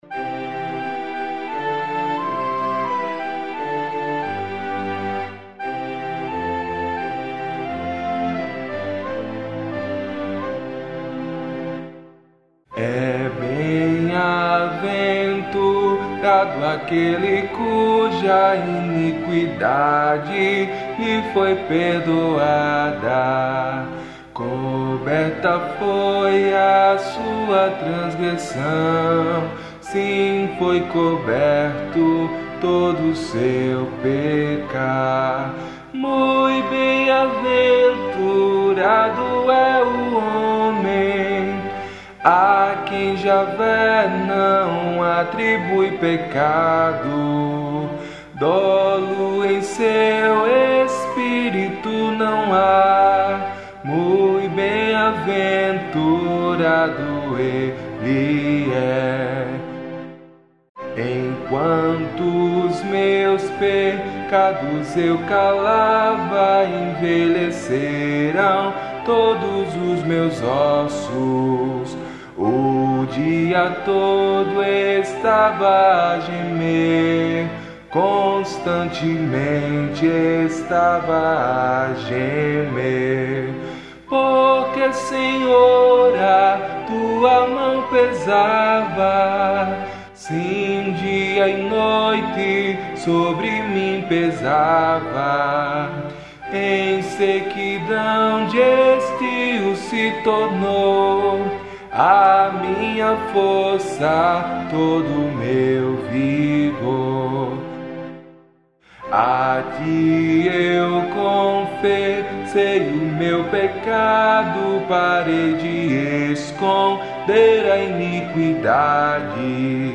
Modo: jônio
Harmonização: Claude Goudimel, 1564
salmo_32A_cantado.mp3